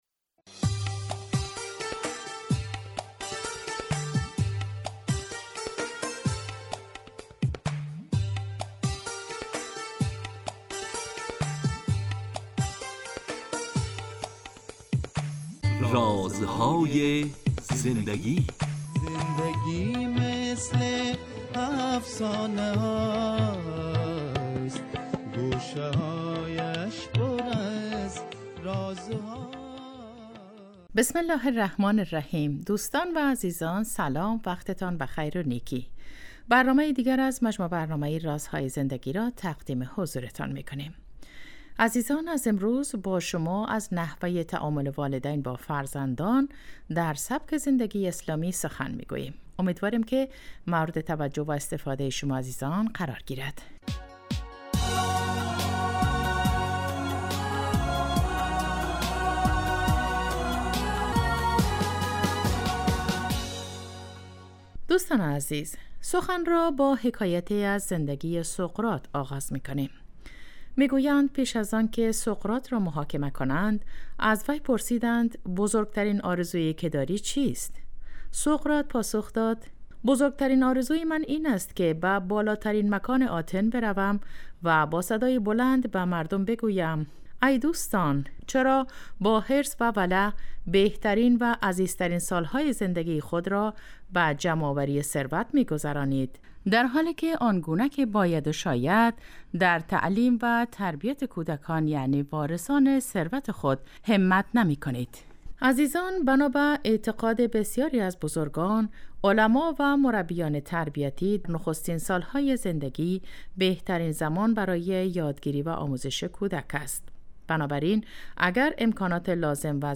این برنامه به مدت 15 دقیقه هر روز ساعت 11:35 به وقت افغانستان از رادیو دری پخش می شود .